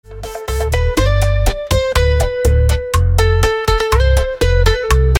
Canción Romántica con Voz Triste y Emotiva
Banda y voz: AI asistida